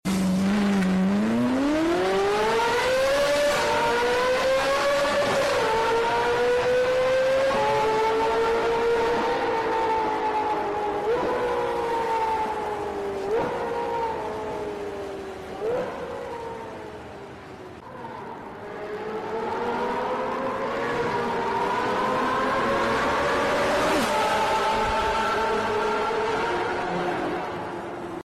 Give me one car with a better sound 🏎 If you love engine sounds then you are on right place. Enjoy videos of V6, V8, V12, rotary engine and many more tuned vehicles.